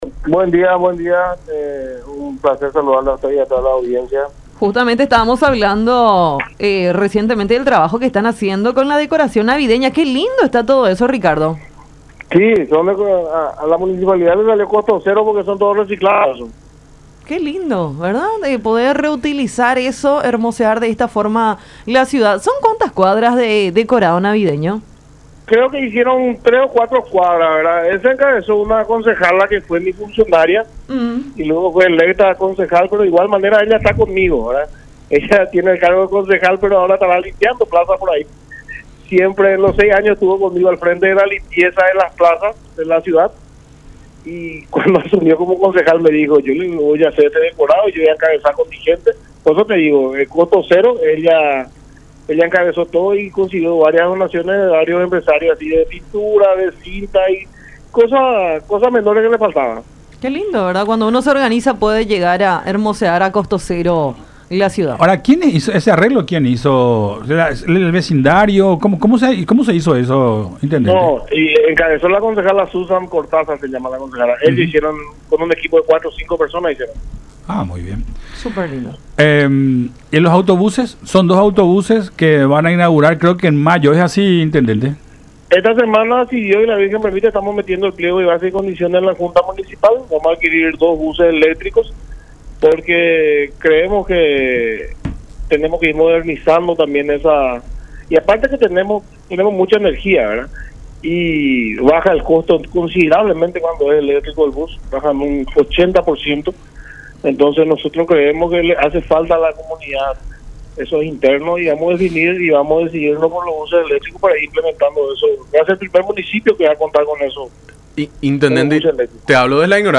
“Vamos a contar con dos buses eléctricos. Creemos que le falta a la comunidad este tipo de buses. Tienen un sistema de cargado rápido de baterías, en un lapso de una hora aproximadamente”, destacó el intendente de Villa Elisa, Ricardo Estigarribia, en charla con Enfoque 800 por La Unión.